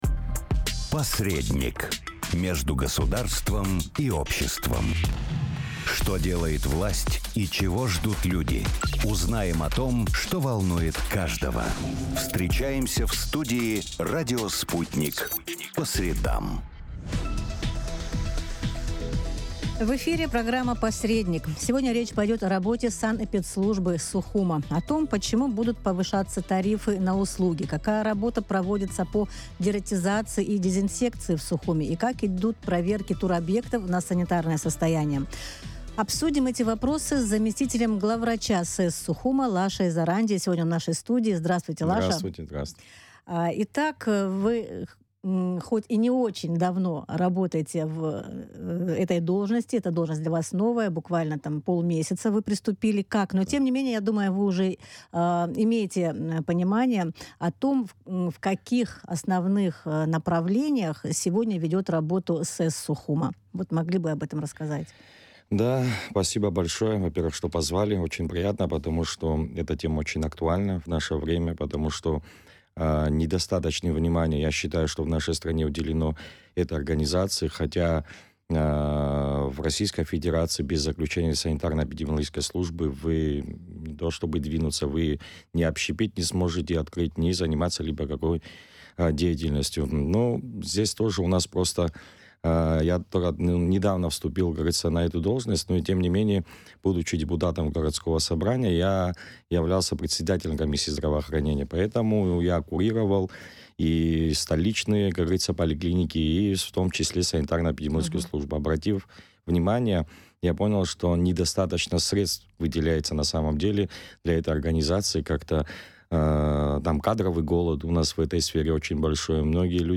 Радио